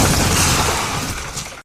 Glass Shatter
Glass Shatter is a free sfx sound effect available for download in MP3 format.
347_glass_shatter.mp3